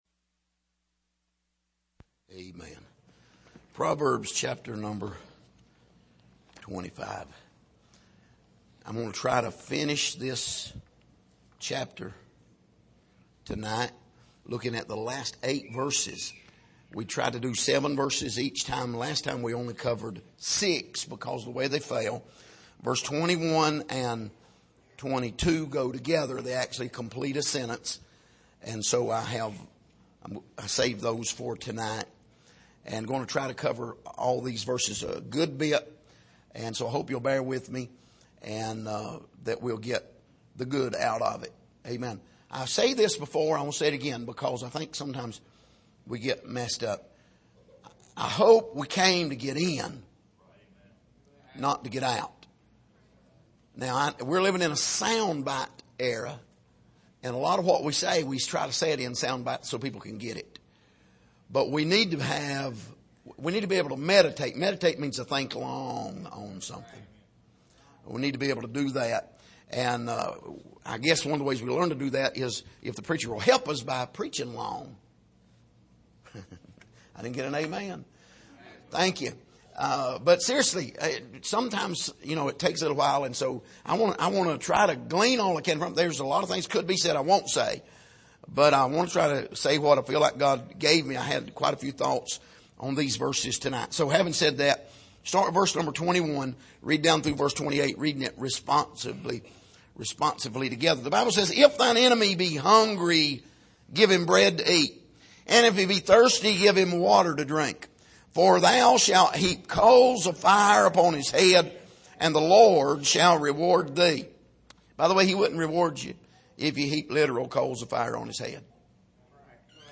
Passage: Proverbs 25:21-28 Service: Sunday Evening